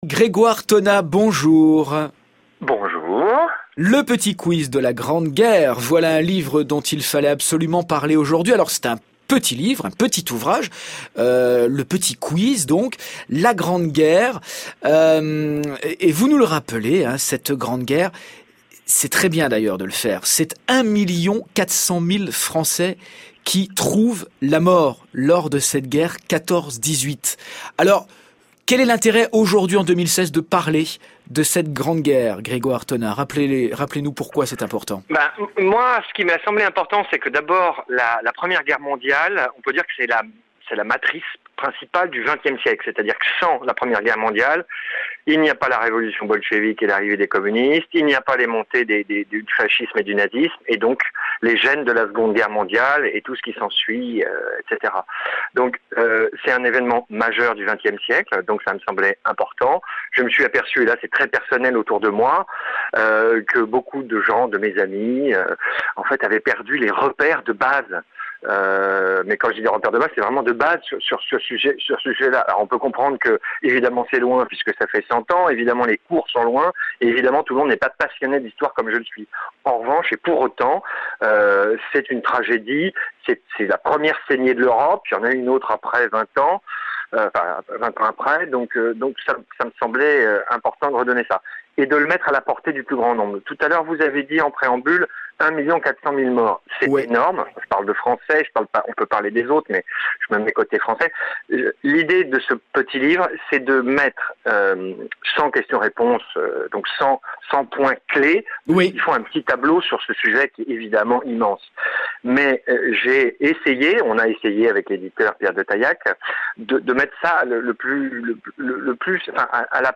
ITW dans l'émission France Bleu et vous